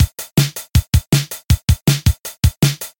・ROM 3 80 年代のドラムサウンドをカスタム
■ ROM3で「MininnDrum」を再生したパターン